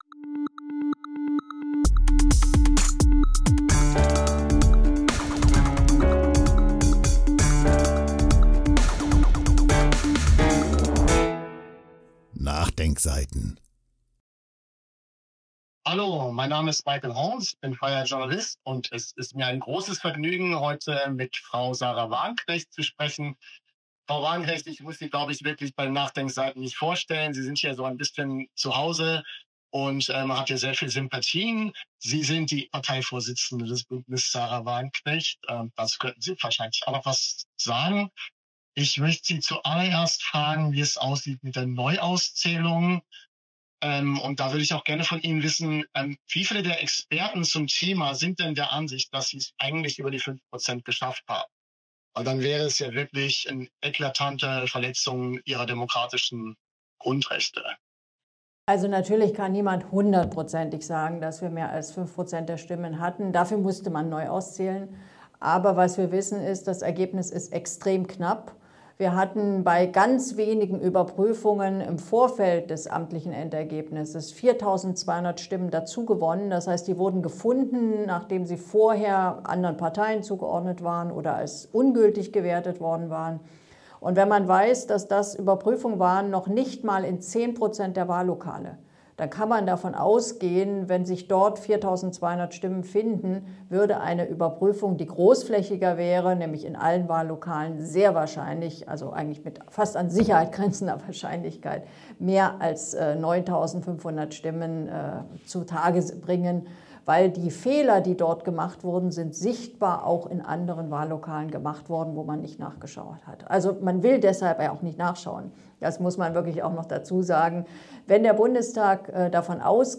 NachDenkSeiten – Die kritische Website > Sahra Wagenknecht im NachDenkSeiten-Interview: Über Kriegstreiberei, soziale Spaltung und den nötigen Kurswechsel